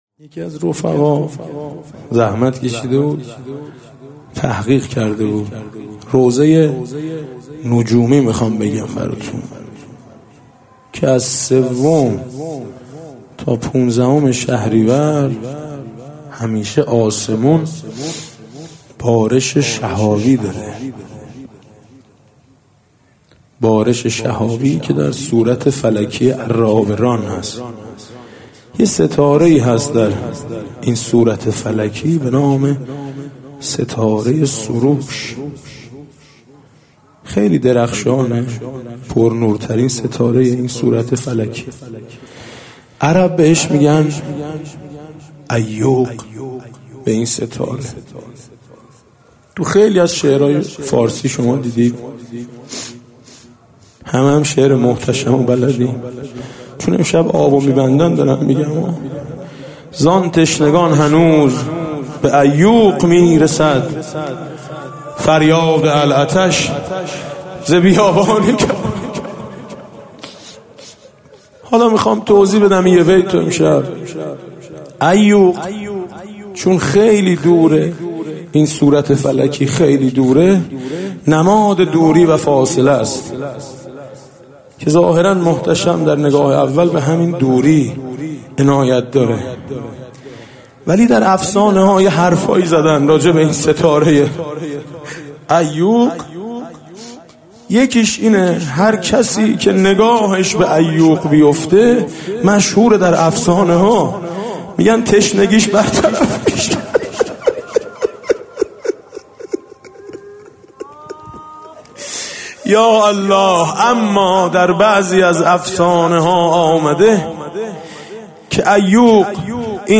محرم 99 - شب هفتم - روضه - ستاره عیوق و تشنگی
محرم 99